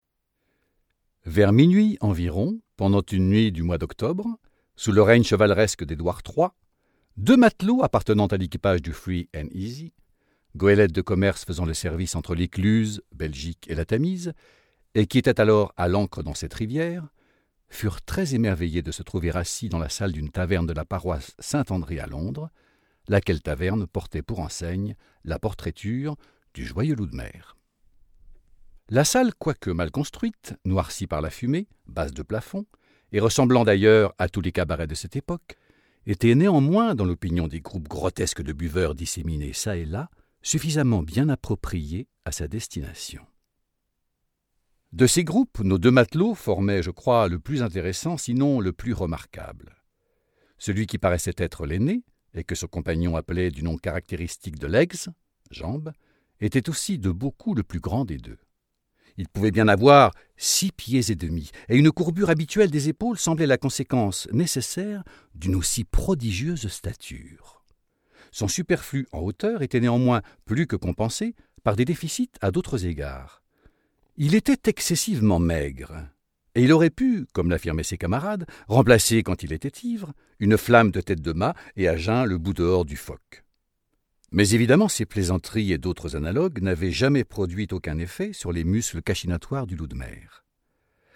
Diffusion distribution ebook et livre audio - Catalogue livres numériques
2009 Doté d'une voix chaleureuse et envoûtante